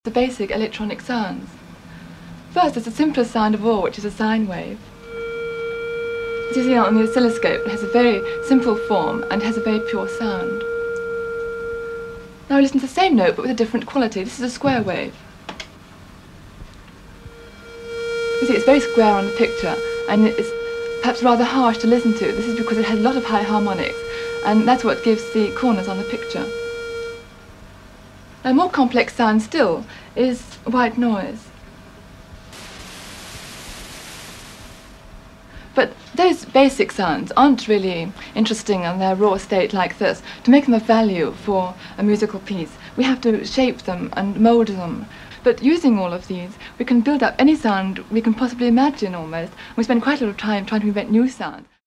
Considered by many as the ‘mother of electronic music’, pioneer Delia Derbyshire made music using electronic sounds before the synthesizer as we know it was even invented. In the above excerpt, she demonstrates the difference between a sine wave, square wave and white noise and explains how shaping and molding basic wave shapes can lead to any sound you can possibly imagine. References: Delia Derbyshire BBC Radiophonic Workshop.